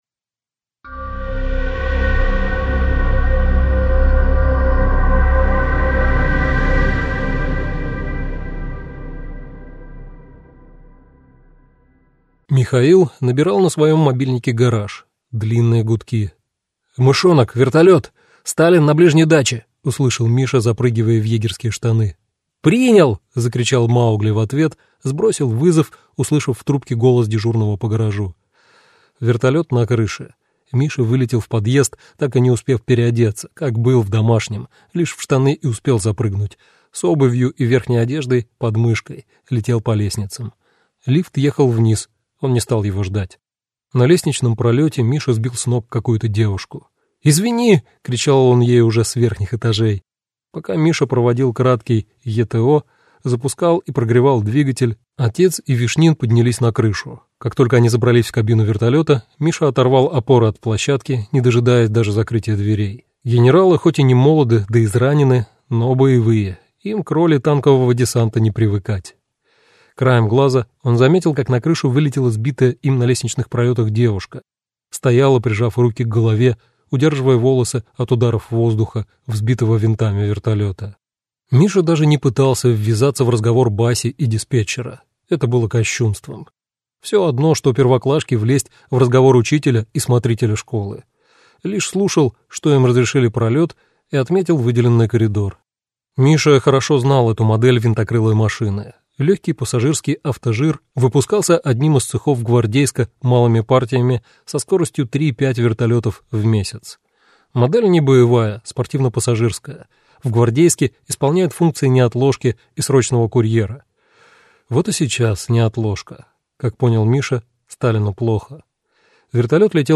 Аудиокнига Сегодня – позавчера. Испытание вечностью | Библиотека аудиокниг